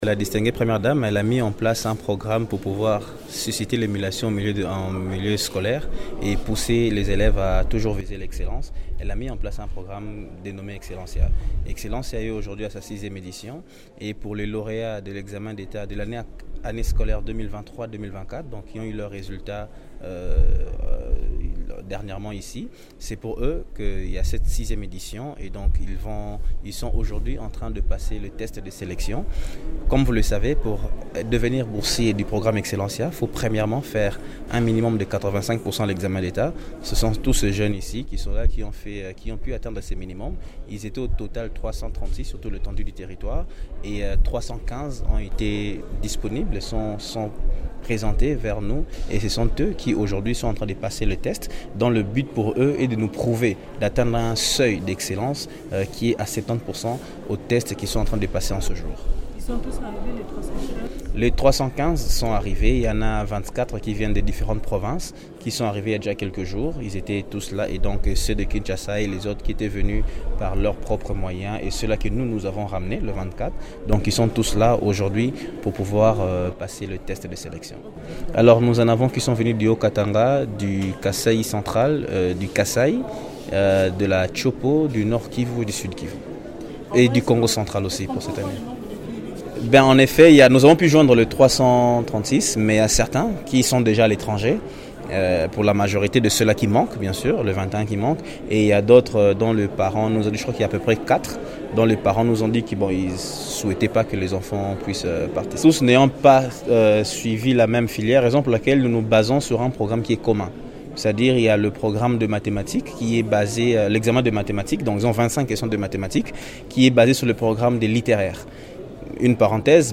donne plus des détails sur ce test d’éligibilité au micro de